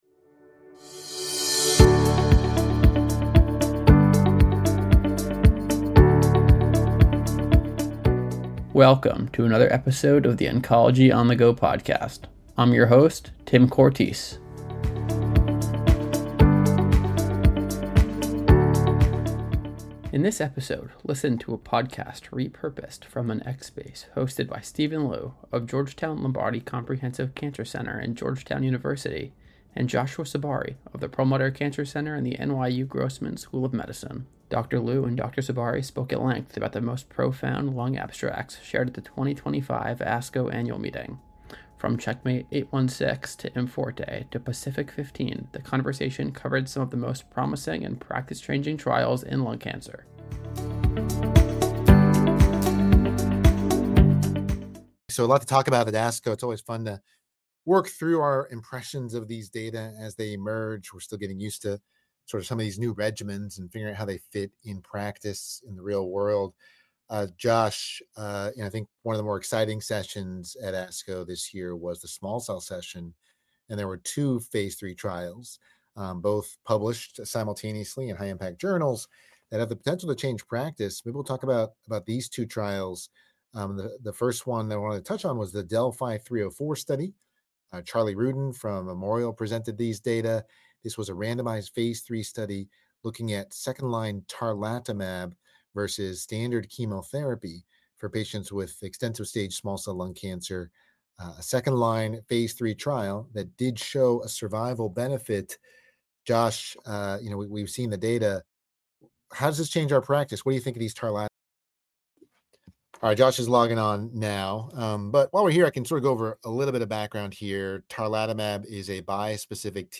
X Spaces discussion